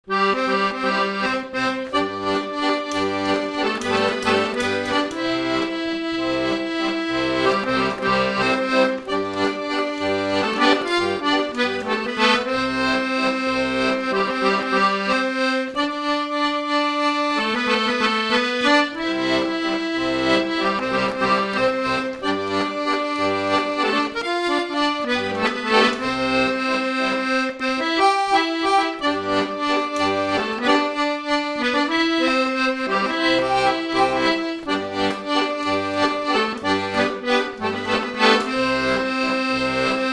Résumé instrumental
danse : mazurka
Pièce musicale inédite